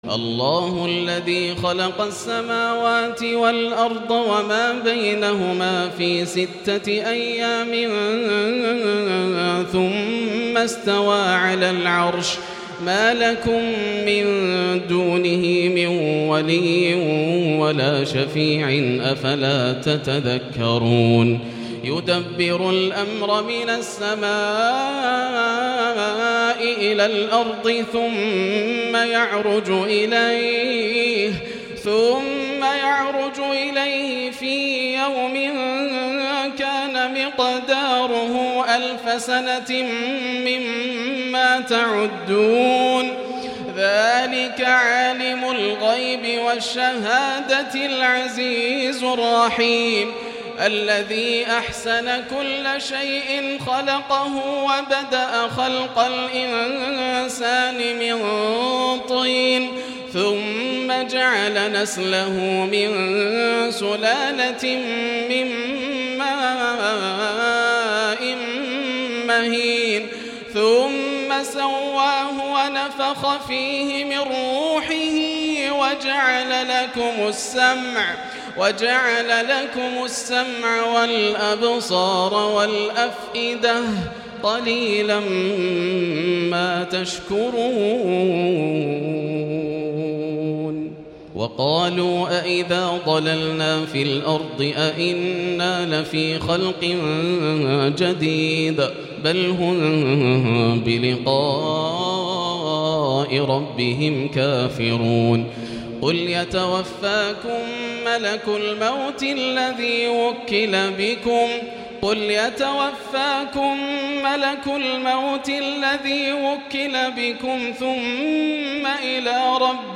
(وَلَوْ تَرَىٰ إِذِ الْمُجْرِمُونَ نَاكِسُو رُءُوسِهِمْ عِندَ رَبِّهِمْ) > الروائع > رمضان 1438هـ > التراويح - تلاوات ياسر الدوسري